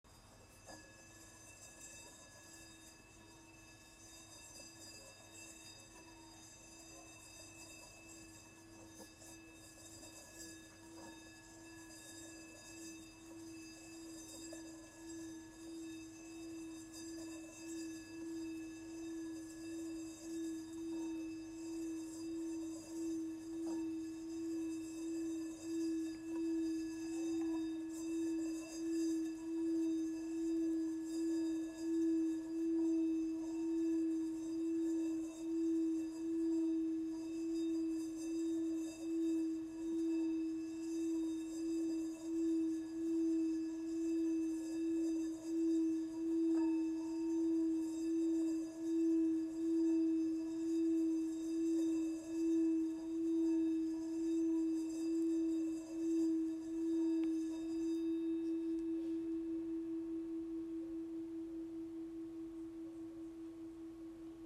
Lyssna på vibrationerna från den 14 cm stora tibetanska Chö-Pa-skålen med en klubba med en diameter på 28 mm:
vibration-col-chopa-14.m4a